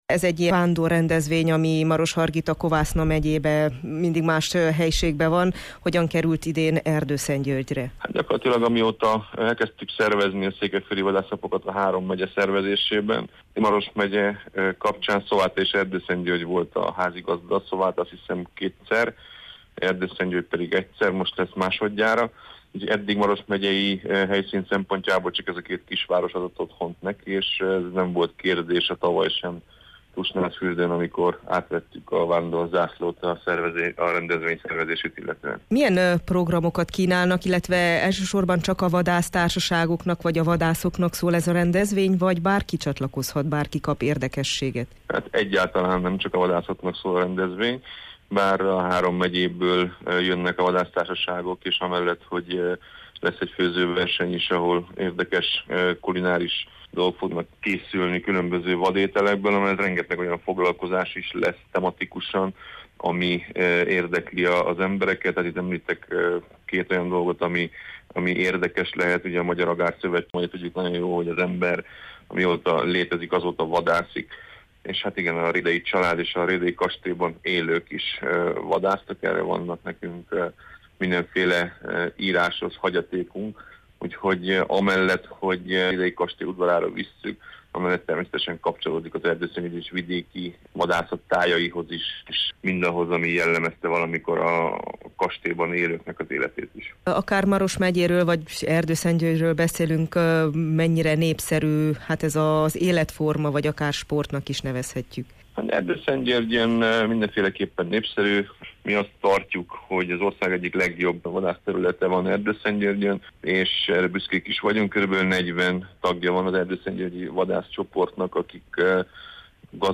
Az esemény október 12-13-án az erdőszentgyörgyi Rhédey-kastélyban lesz és a hagyományos Szent Hubertus-misével kezdődik. Csibi Attila Zoltánt, Erdőszentgyörgy polgármesterét, az erdőszentgyörgyi Cervus Vadásztársaság elnökét kérdezte